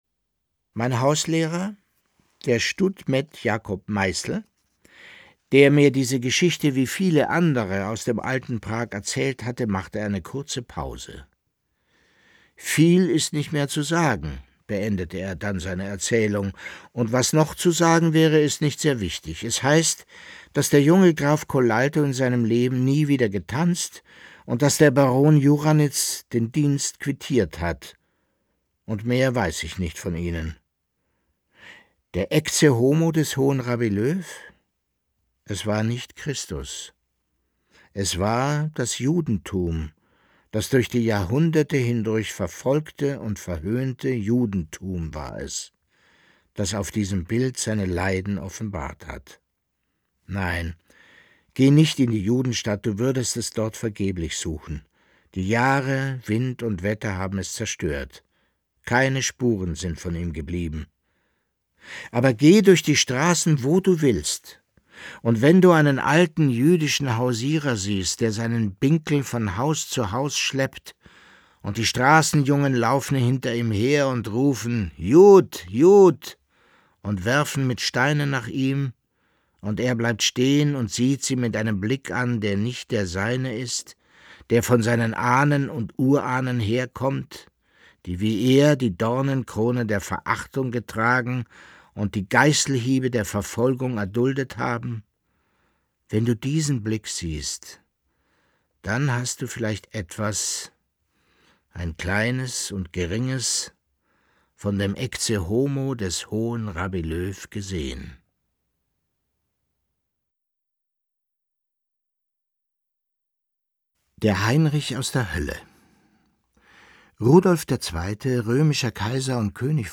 Leo Perutz: Nachts unter der steinernen Brücke (6/25) ~ Lesungen Podcast